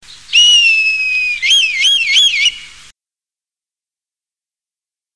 Milan royal
milan-royal.mp3